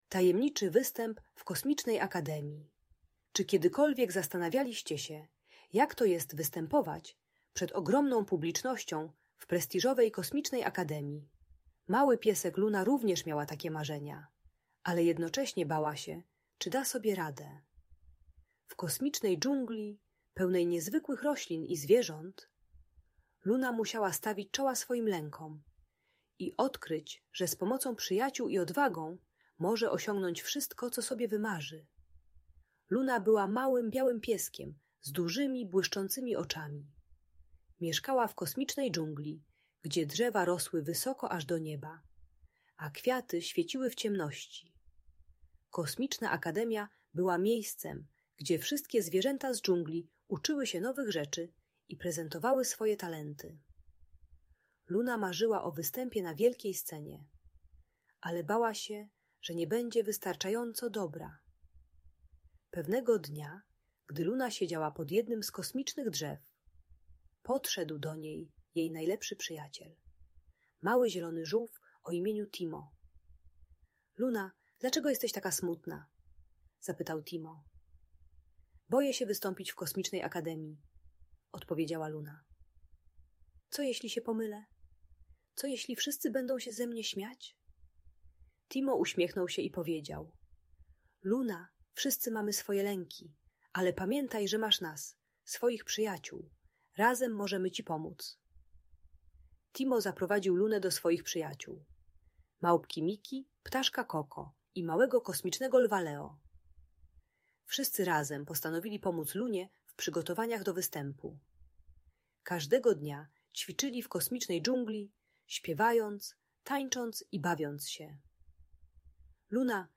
Tajemniczy Występ w Kosmicznej Akademii - Audiobajka